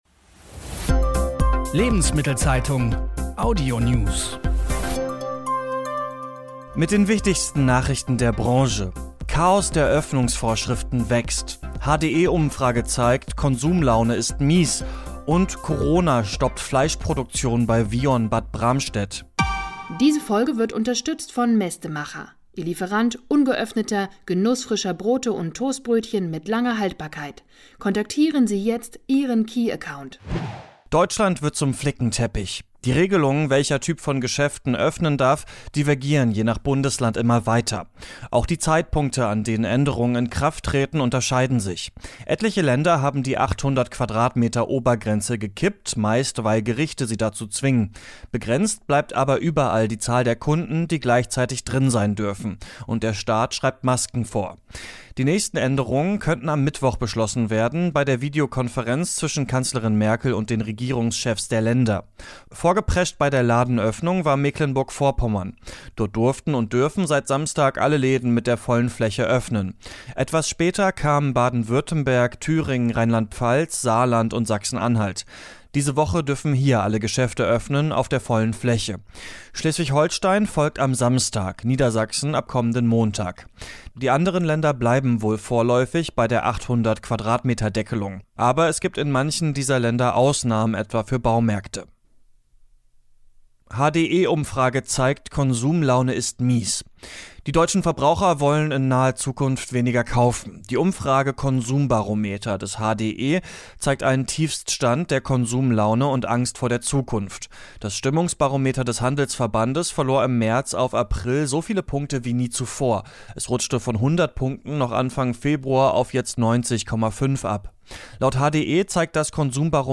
Wirtschaft , Nachrichten